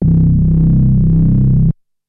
OSCAR OBOE 1.wav